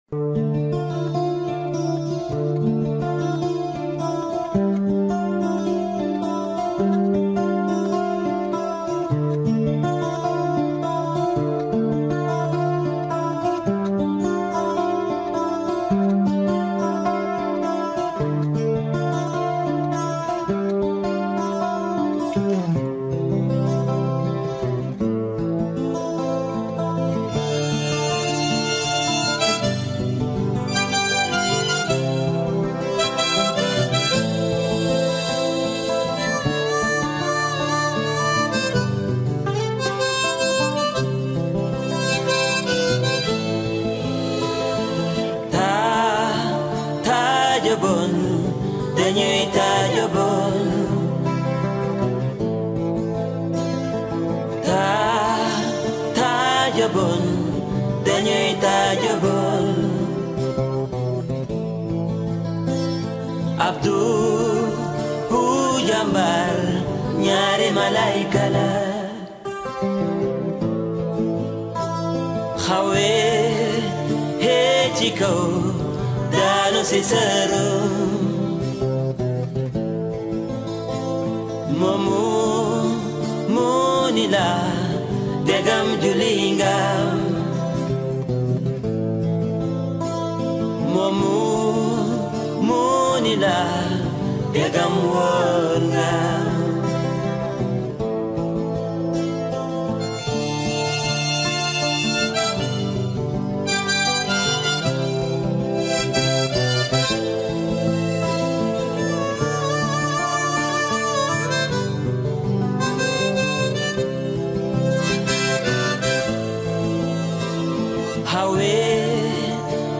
Senegalese zanger